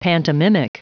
Prononciation audio / Fichier audio de PANTOMIMIC en anglais
Prononciation du mot : pantomimic